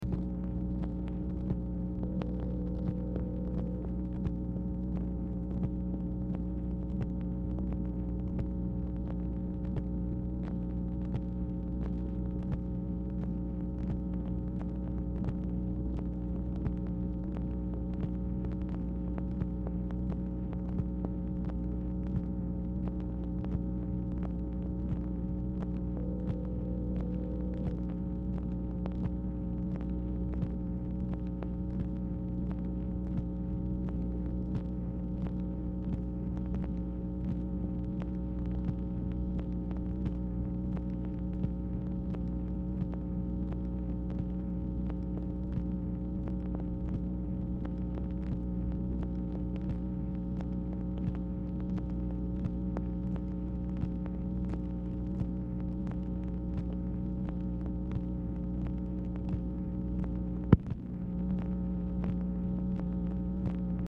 Telephone conversation # 11370, sound recording, MACHINE NOISE, 1/18/1967, time unknown | Discover LBJ
Format Dictation belt
Specific Item Type Telephone conversation